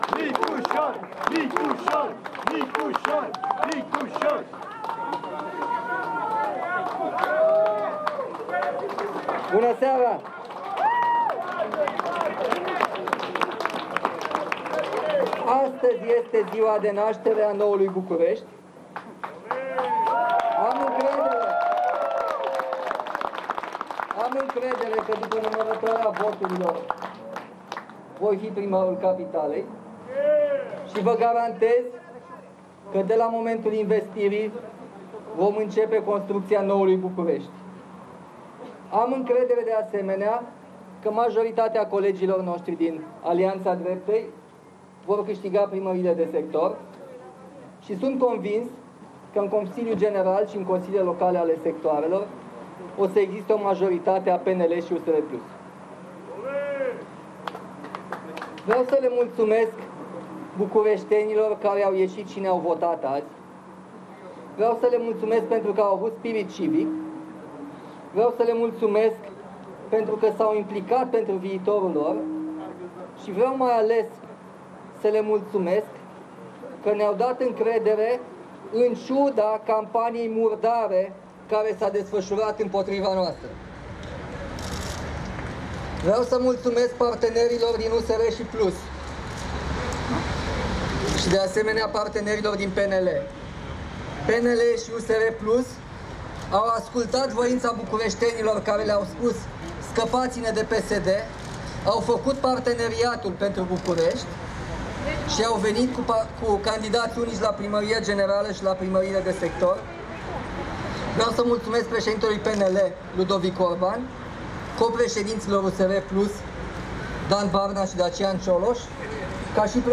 Susţinut de PNL şi USR PLUS pentru funcţia de primar general al Capitalei, Nicuşor Dan a declarat, duminică seara, după închiderea urnelor, că este încrezător că după numărătoarea voturilor va fi noul primar al Bucureştiului.
“Astăzi este ziua de naştere a noului Bucureşti. Am încredere că după numărătoarea voturilor voi fi primarul Capitalei”, a afirmat deputatul, în cadrul unei declaraţii de presă susţinute la sediul său de campanie.